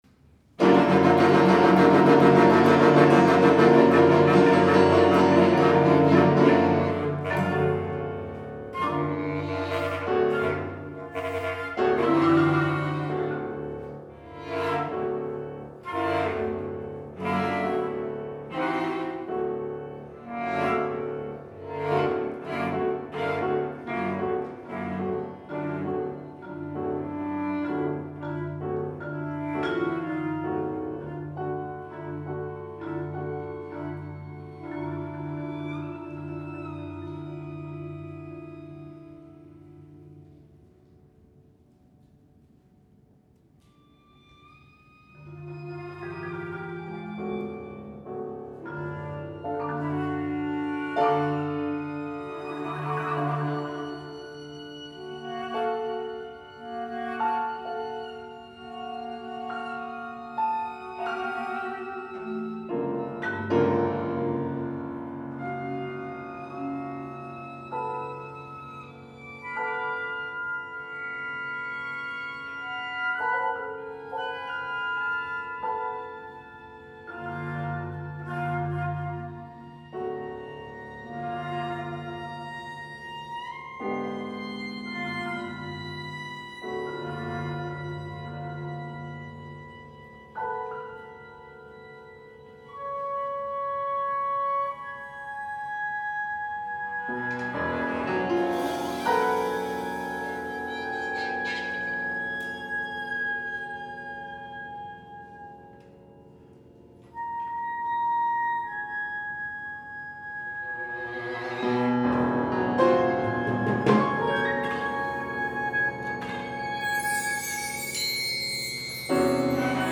for sextet